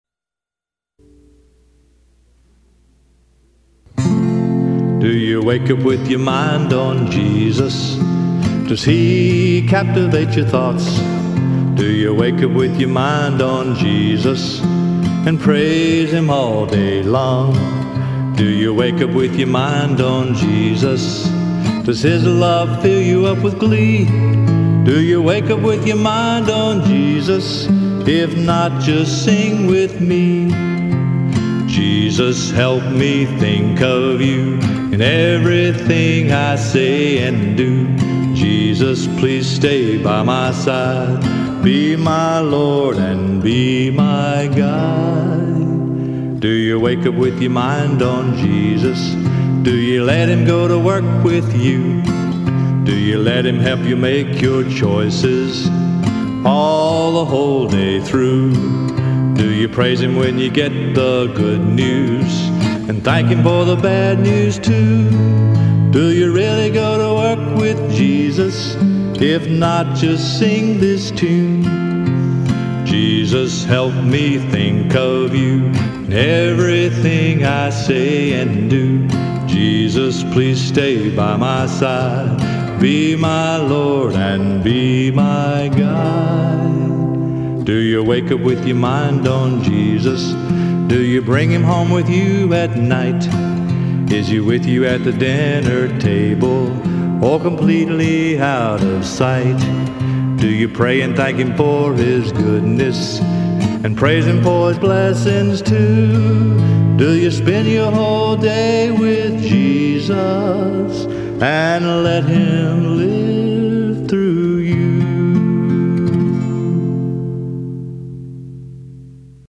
Vocalist/Guitar/Tambourine
Harmony
Bass Guitar